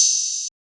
Open Hats
[openhat] (4) had enough.wav